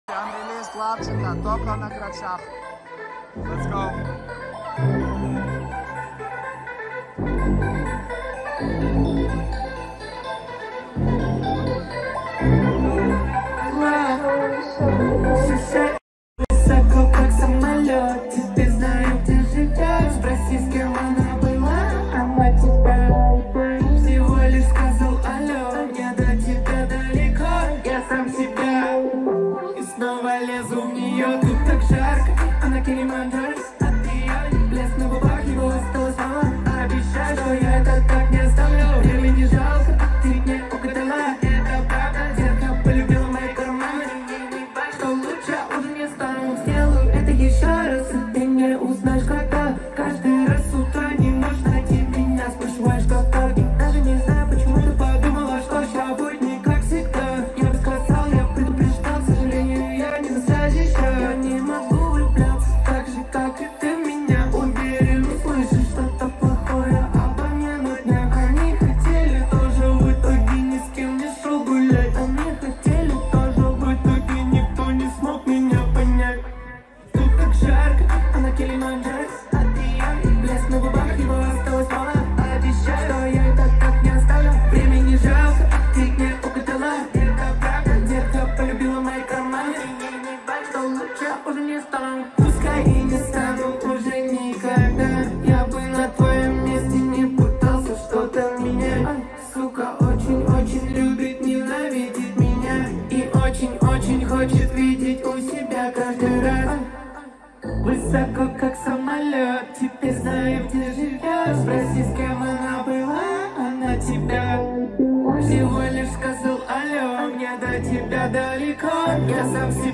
• Качество: 223 kbps, Stereo